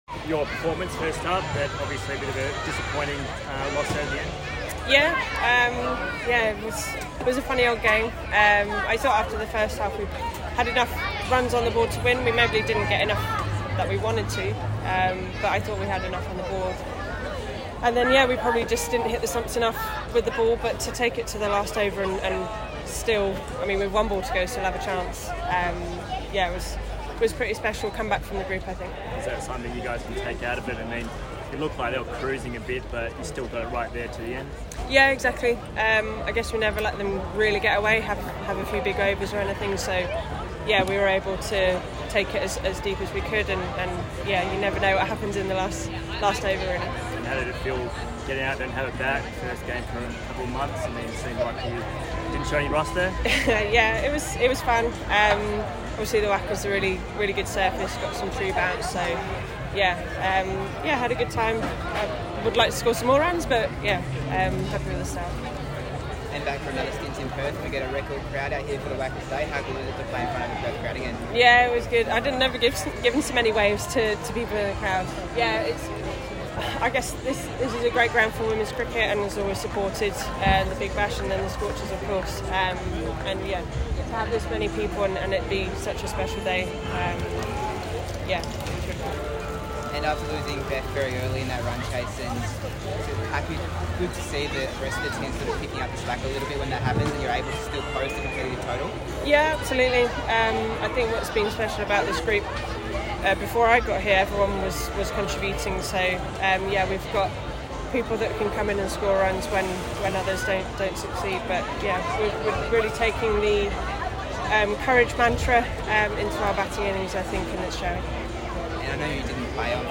Scorchers allrounder Nat Sciver Brunt (53 off 33) spoke to media after their six -wicket defeat to Adelaide Strikers at the WACA (CA)